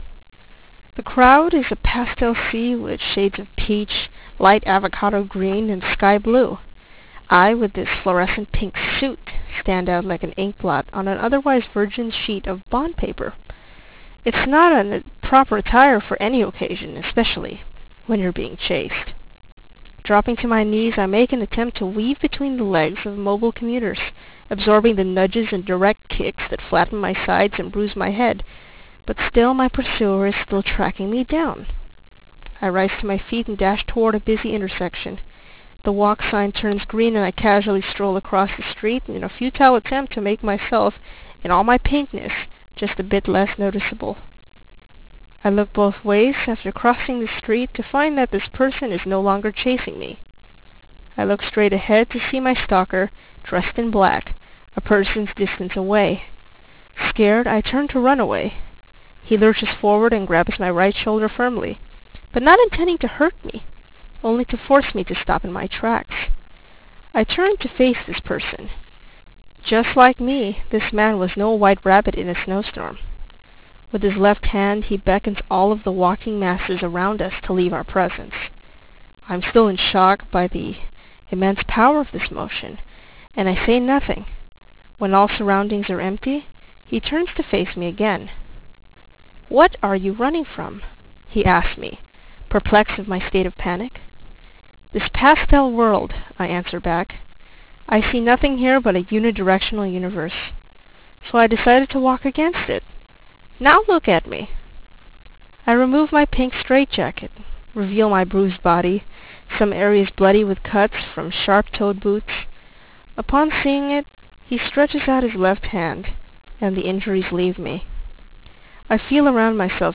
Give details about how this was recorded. Any distortion does not reflect the actual CD track. (It is only a consequence of compression.)